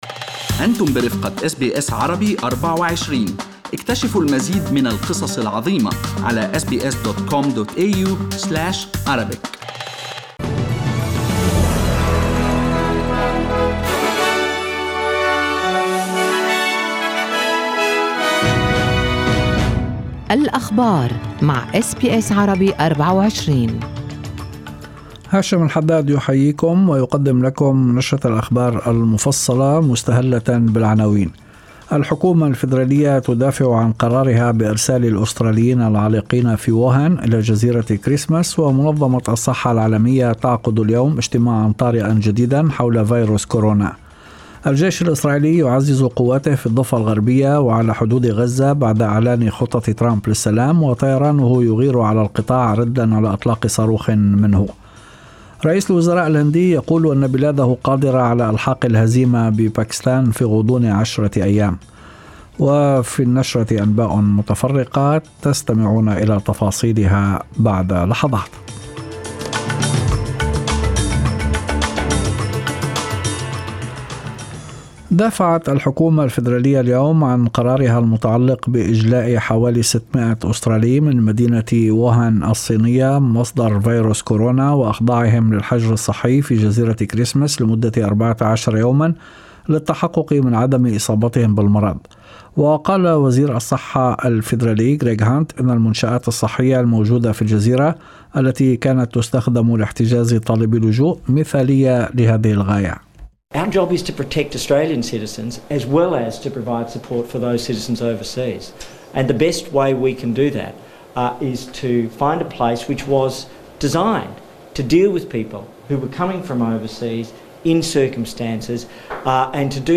نشرة أخبار المساء 30/01/2020
Arabic News Bulletin Source: SBS Arabic24